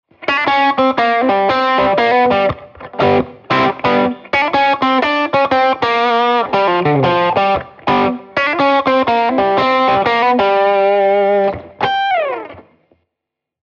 Nos vamos a un plano más solista con esa blue note en el traste 6.
Atención también al juego de 3ra menor-3ra mayor de G al comienzo del compás 2.
Ten en cuenta que estamos en la posición universal pentatónica, referencia traste 3, y es un ejercicio en el que permanecemos estáticos en torno al G (grado I).